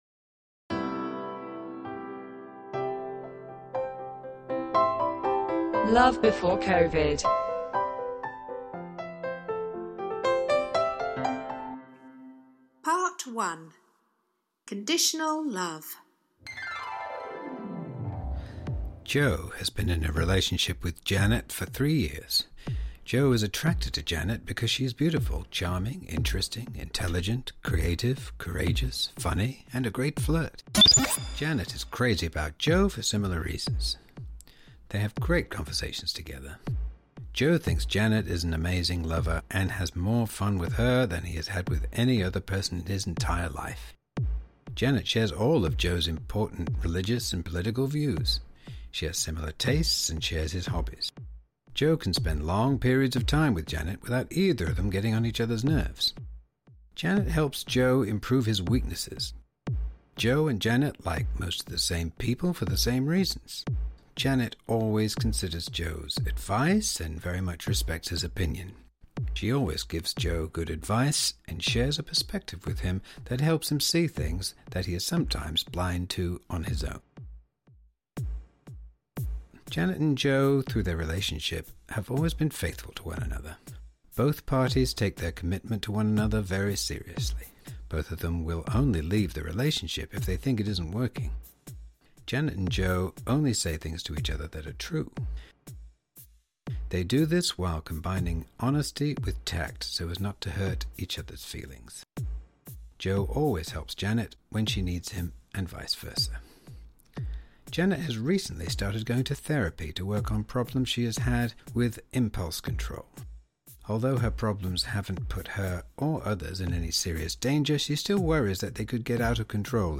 A FEATURE FILM FOR YOUR EARS
This is done by eavesdropping on real-time exchanges between the 3 characters.